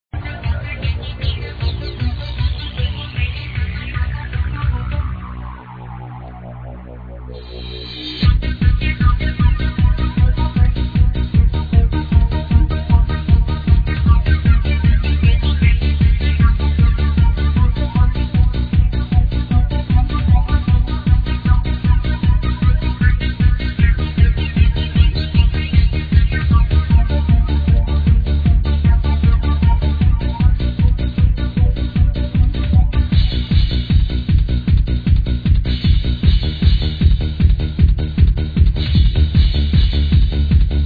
sounds like something from Bonzai back in the days.
it is just so uplifting...damn i need it